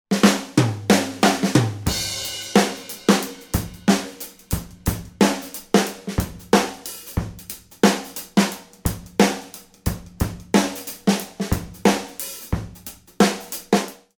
I’ve been away from Hometracked for the last week because I had a chance to record in a great space: A Muskoka cottage with 14-foot cathedral ceilings and all-pine interior.
First, though, here’s a rough mix from one of the drum tracks I recorded at the cottage: [download MP3]